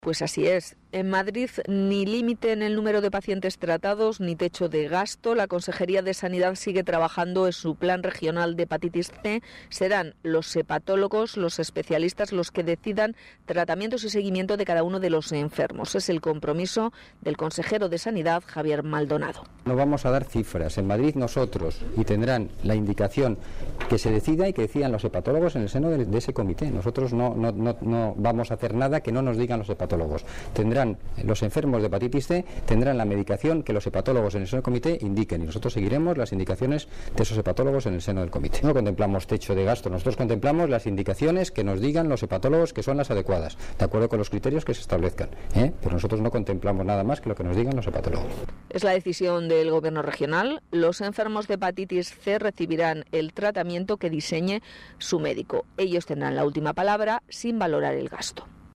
Así lo ha manifestado en declaraciones a los medios de comunicación tras visitar el Hospital Gregorio Marañón donde se han reformado siete quirófanos y preguntado por el planteamiento de la Comunidad respecto a los enfermos de Hepatitis C.